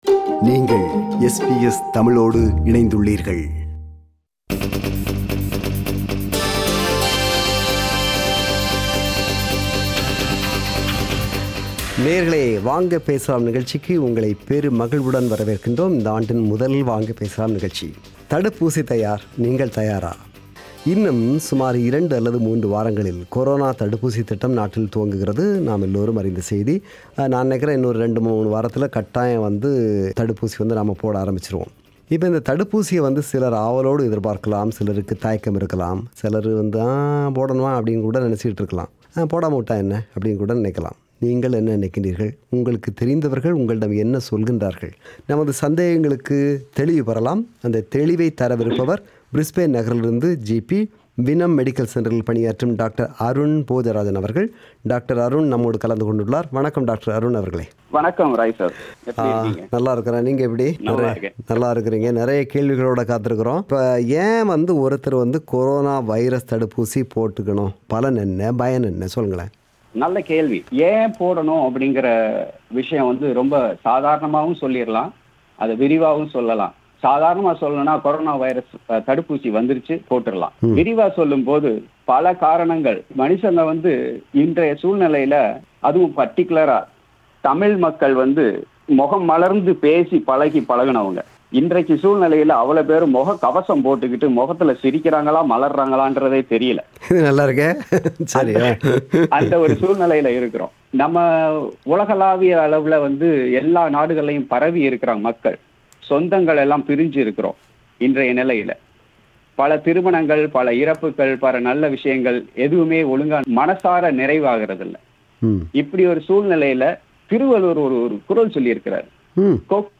This is the compilation of comments shared by our listeners in “Vanga Pesalam” program on Sunday (31 January, 2021).